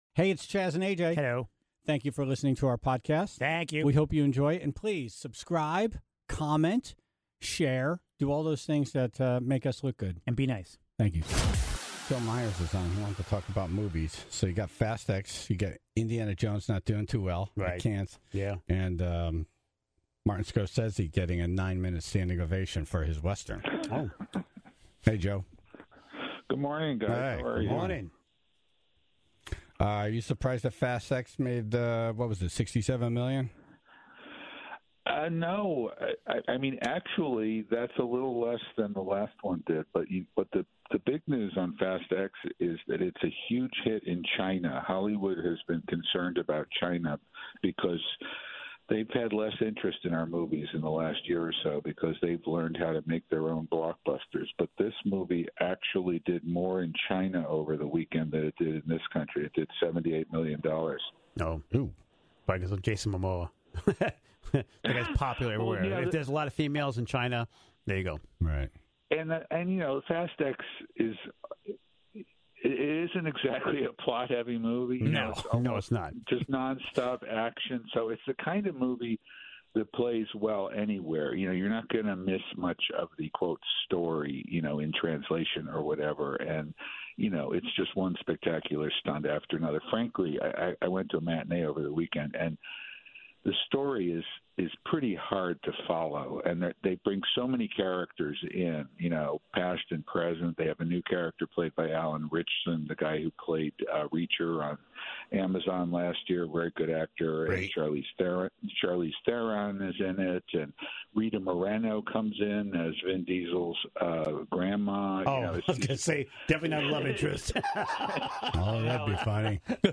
Tribe members shared their moments of glory, embarrassment, and indecent exposure.